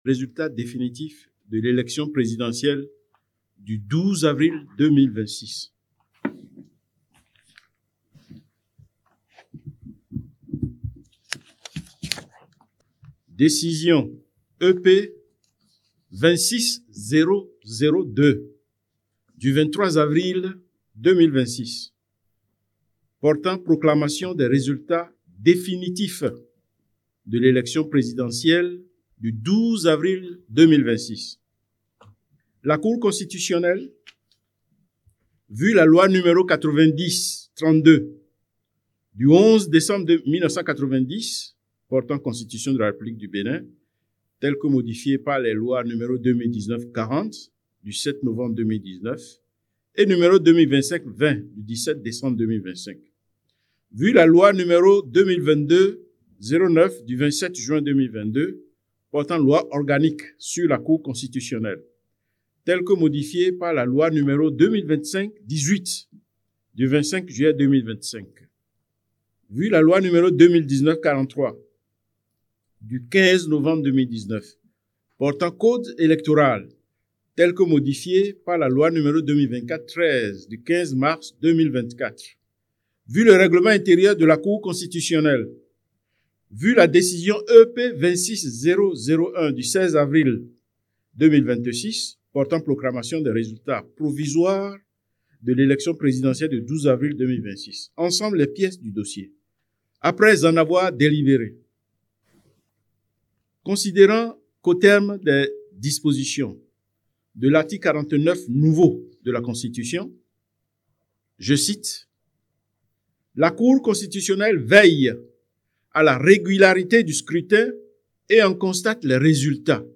Écoutez la Décision lue par le Président de la Haute juridiction, Pr Cossi Dorothé SOSSA 👇👇👇👇